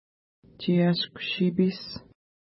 ID: 580 Longitude: -60.0977 Latitude: 52.8846 Pronunciation: tʃi:ja:sku-ʃi:pi:s Translation: Gull River (small) Feature: river Explanation: Named in reference to lake Tshiashku-nipi (no 98) from which it flows.